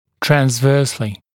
[trænz’vɜːslɪ][трэнз’вё:сли]в транзверзальном направлении, в поперечном направлении